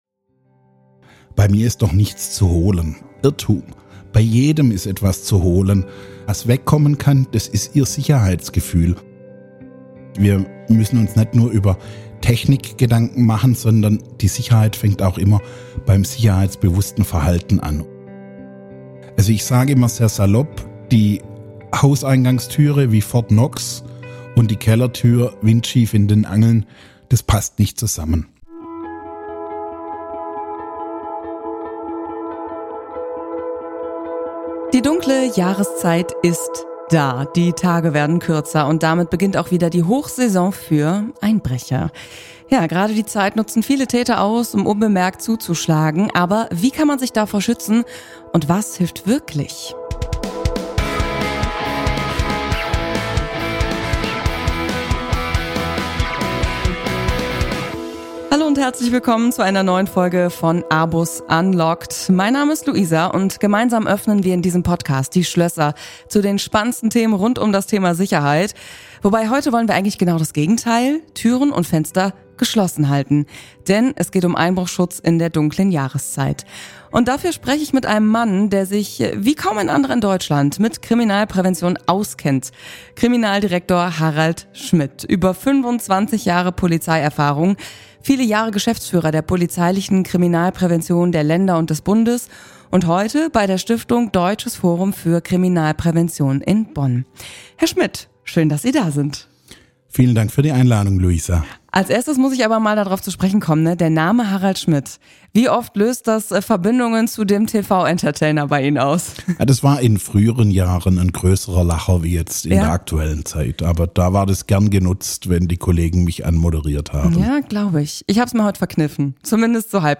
Ein informativer, praxisnaher Austausch für alle, die sicher durch Herbst und Winter kommen wollen.